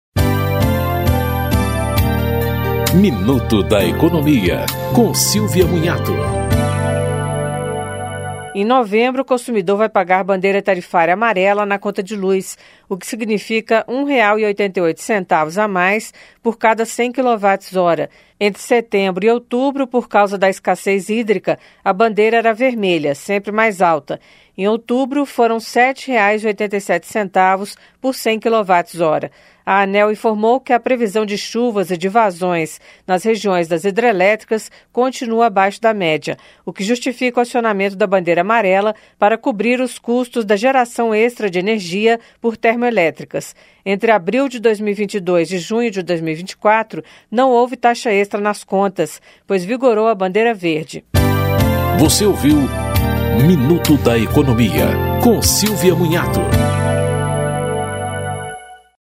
Programas da Rádio Câmara